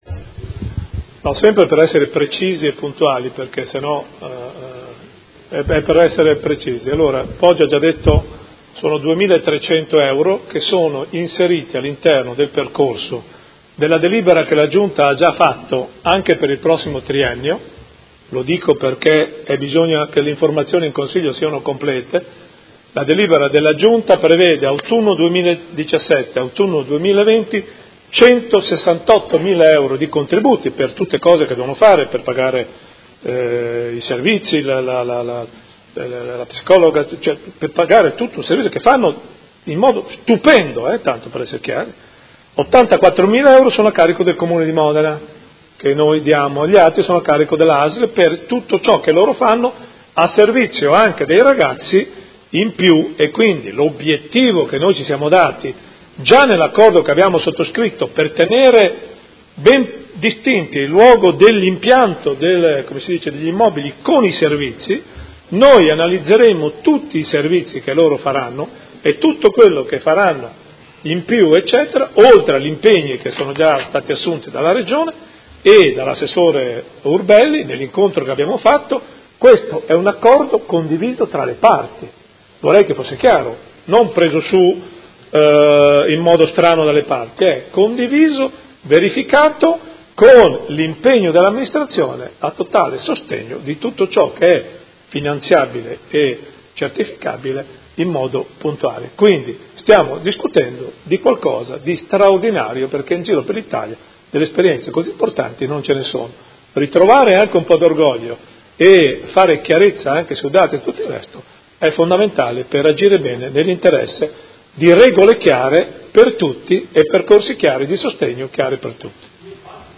Seduta del 23/11/2017 Dichiarazione di voto.